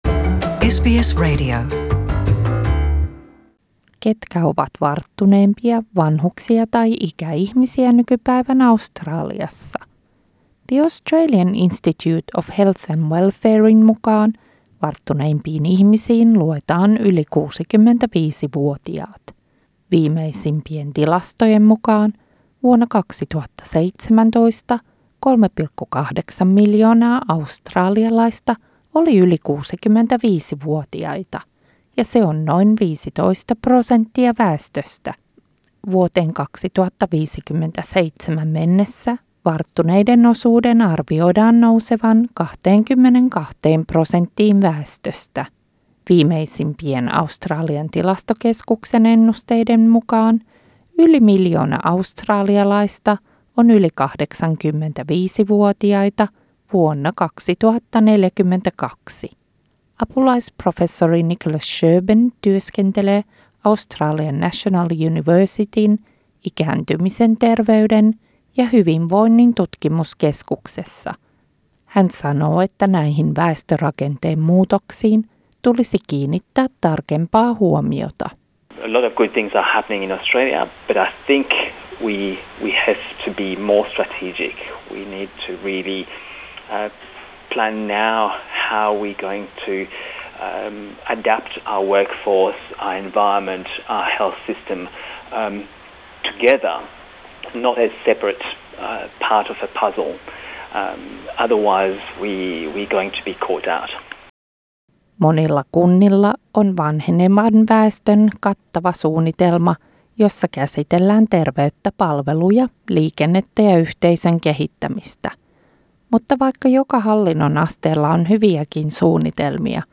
Australialaisten elinajanodote on yksi korkeimmista maailmassa ja täällä pysytään terveempinä yhä kauemmin. Tässä raportissa tarkastellaan ikääntymiseen liittyviä yhteiskunnallisia haasteita.